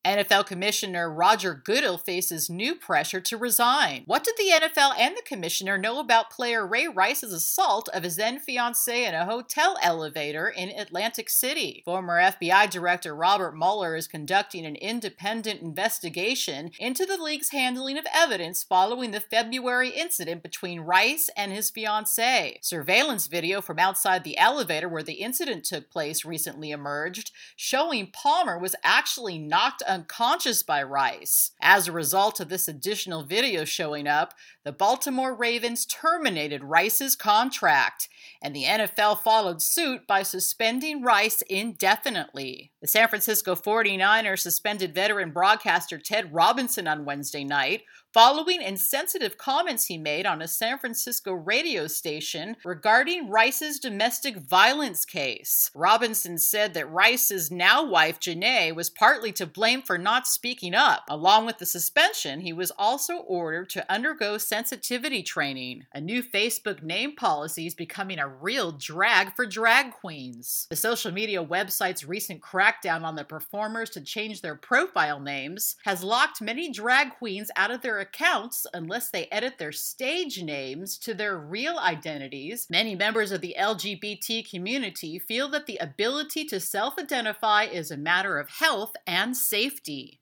help i still can not get a good recording